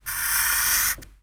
Escribir una línea
Sonidos: Acciones humanas
Sonidos: Oficina